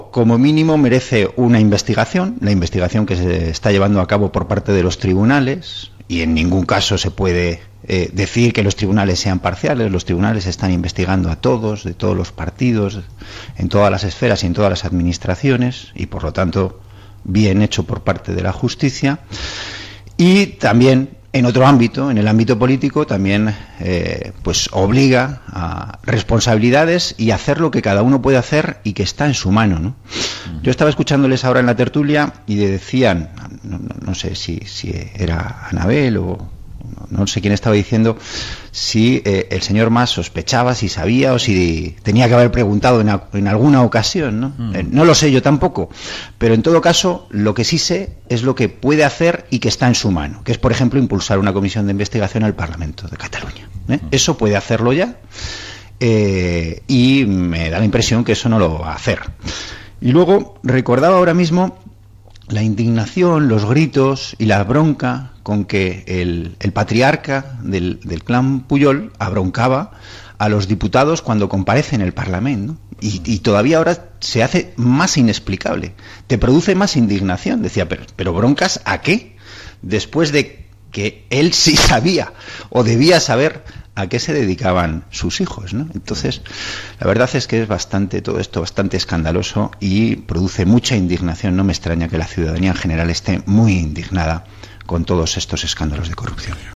Fragmento de la entrevista